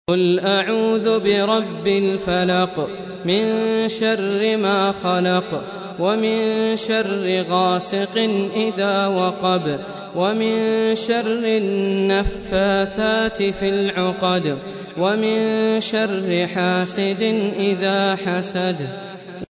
Surah Repeating تكرار السورة Download Surah حمّل السورة Reciting Murattalah Audio for 113. Surah Al-Falaq سورة الفلق N.B *Surah Includes Al-Basmalah Reciters Sequents تتابع التلاوات Reciters Repeats تكرار التلاوات